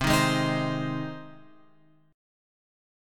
Cm#5 chord {8 6 6 8 x 8} chord